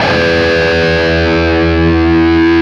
LEAD E 1 CUT.wav